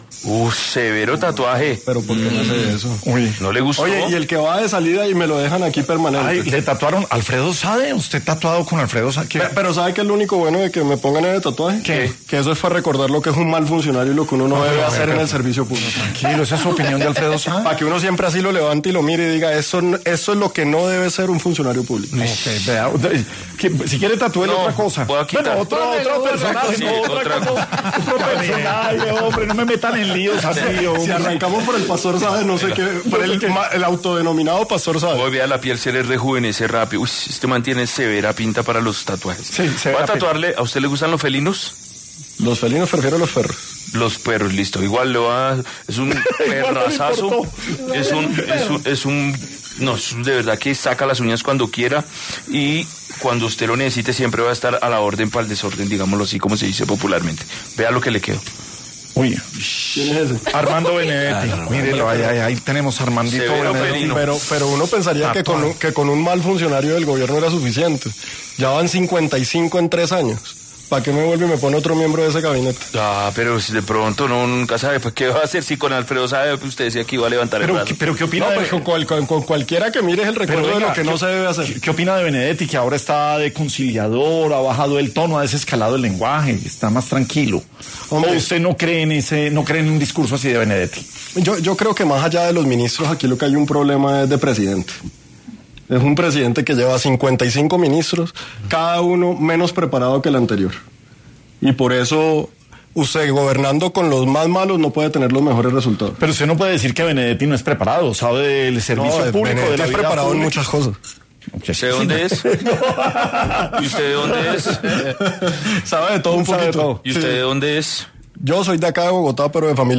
En este contexto, el aspirante a la presidencia, Daniel Palacios, pasó por los micrófonos de La Luciérnaga, para abordar ese tema.